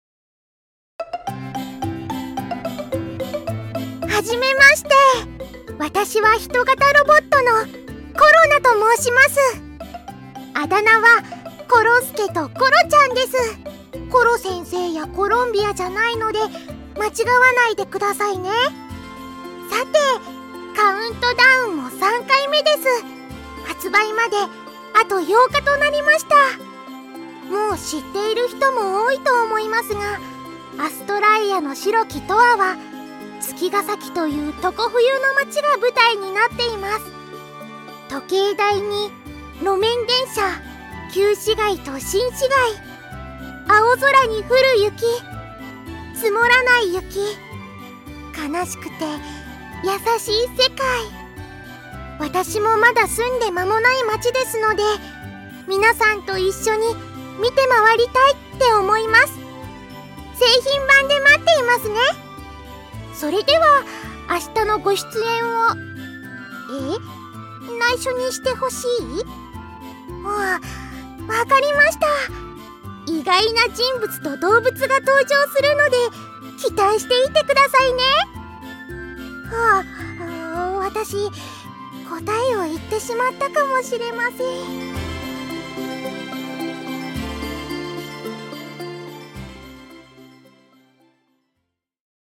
『アストラエアの白き永遠』 発売8日前カウントダウンボイス(コロナ)を公開